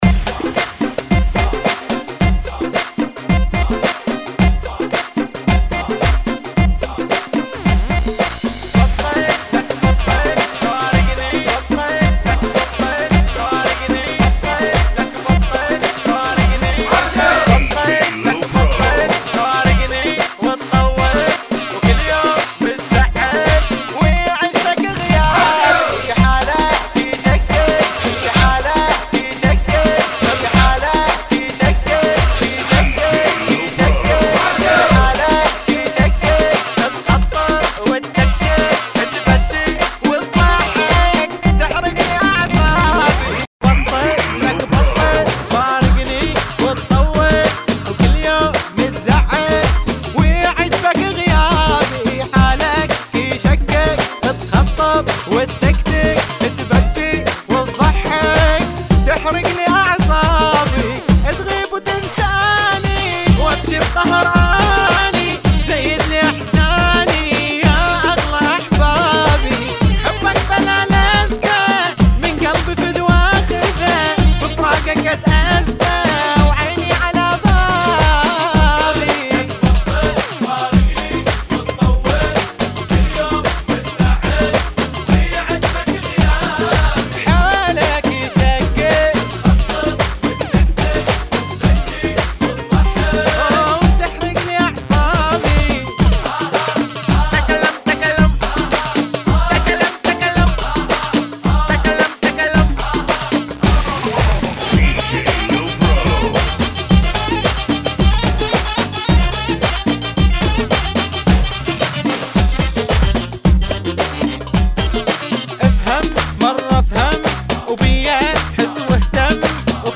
[ 110 bpm ] FunKy